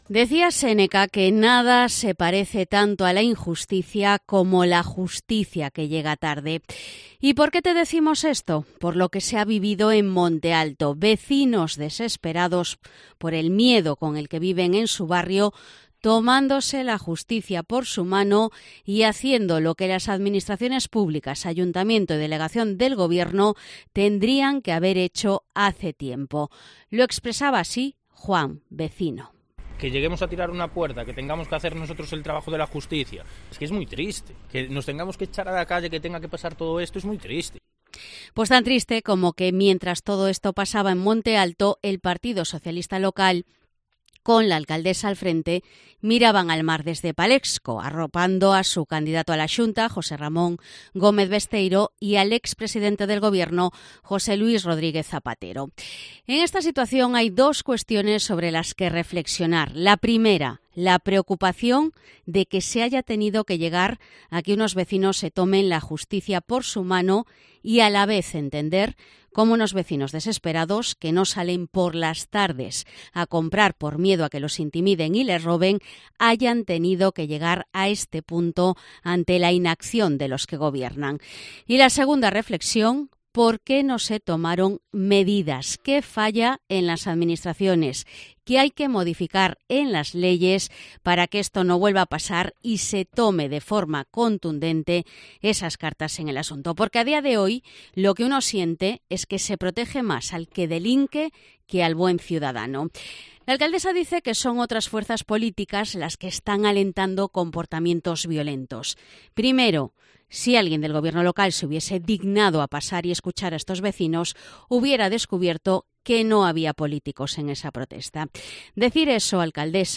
Editorial sobre la situación en Monte Alto